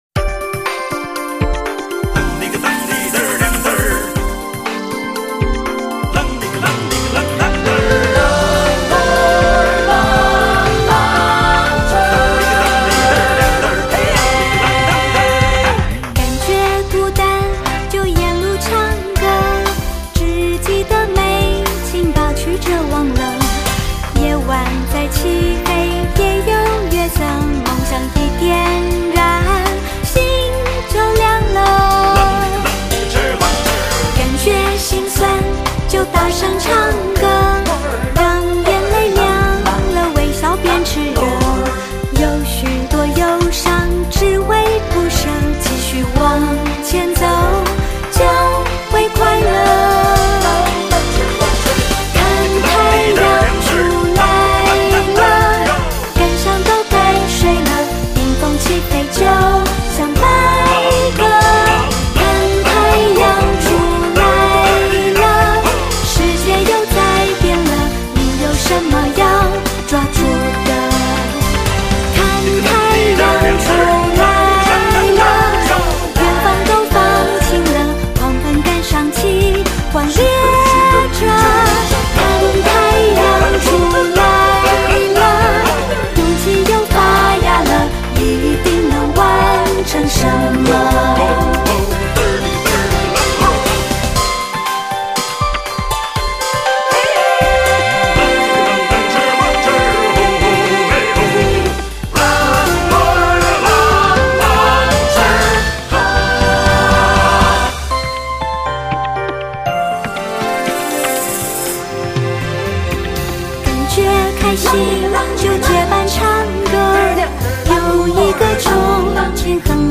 音乐风格: 流行